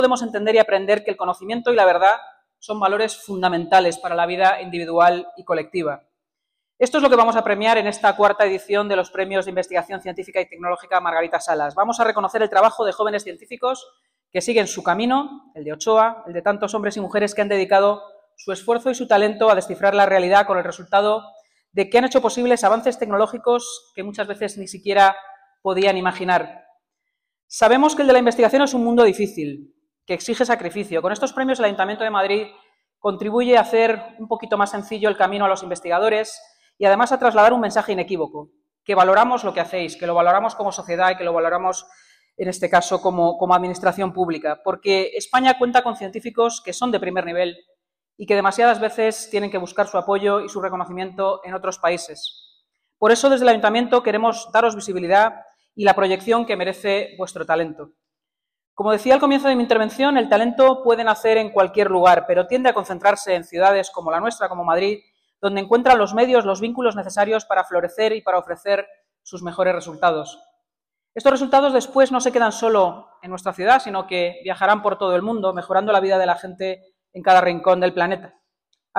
Nueva ventana:La vicealcaldesa de Madrid, Inma Sanz, en su intervención durante la IV edición de los Premios Margarita Salas del Ayuntamiento de Madrid